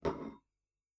clamour4.wav